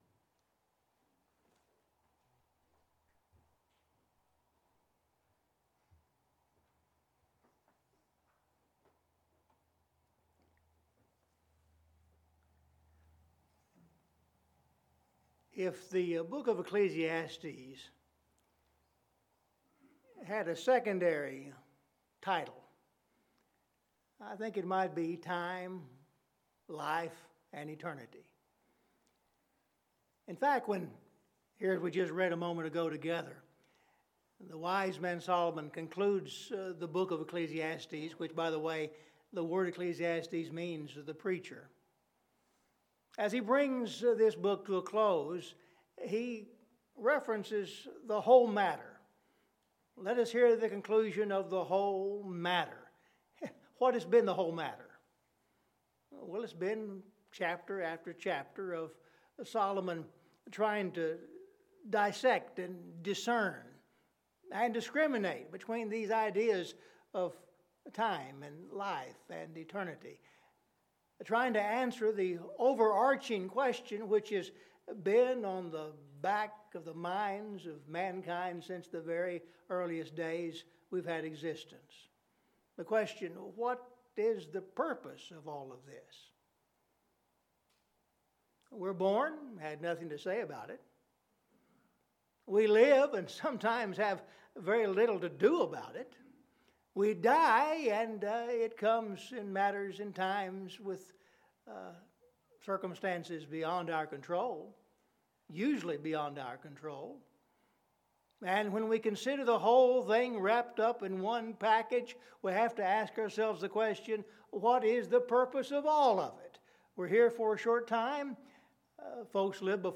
Scripture Reading – Ecclesiastes 12:11-14